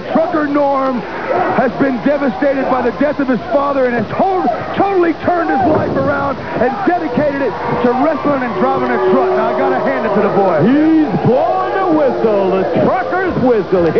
Here’s Jimmy Garvin and Lance Russell
Jimmy Garvin (attempting – in vain – to sound sincere): “Trucker Norm has been devastated by the death of his father and has totally turned his life around, and has dedicated it to wrestling and driving a truck.
Lance Russell (sounding like a major dork): “He’s blowin’ the whistle, the trucker’s whistle!”